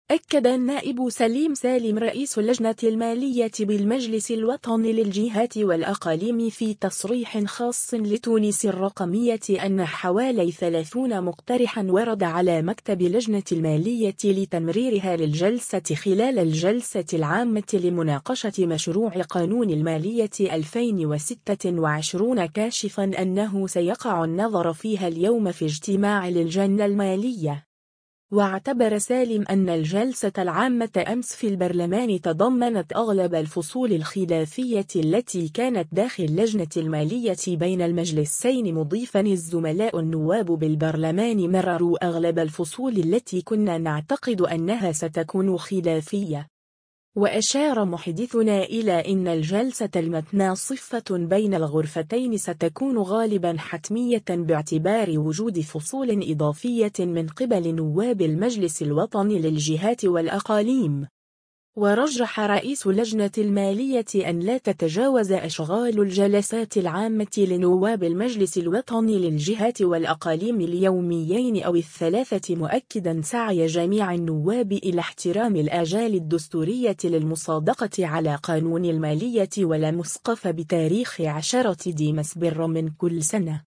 أكد النائب سليم سالم رئيس لجنة المالية بالمجلس الوطني للجهات والأقاليم في تصريح خاص لـ”تونس الرقمية” أن حوالي 30 مقترحا ورد على مكتب لجنة المالية لتمريرها للجلسة خلال الجلسة العامة لمناقشة مشروع قانون المالية 2026 كاشفا أنه سيقع النظر فيها اليوم في اجتماع للجنة المالية.